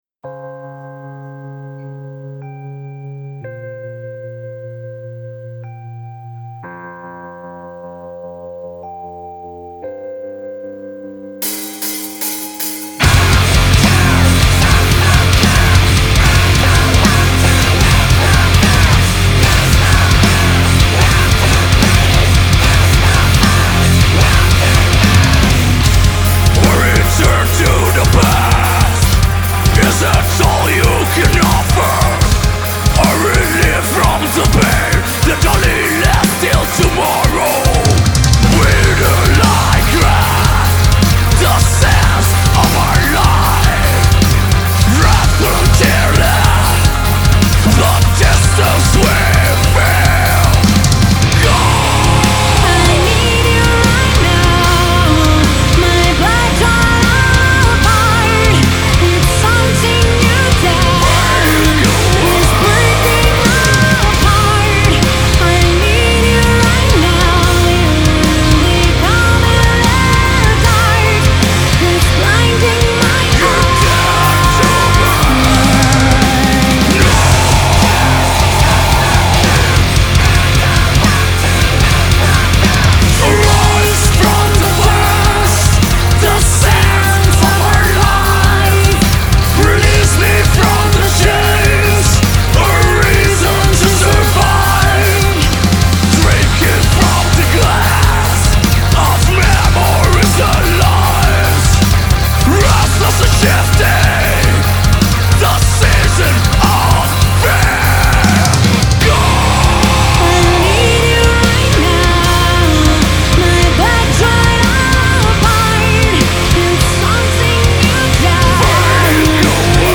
Genre : Rock, Hard Rock, Metal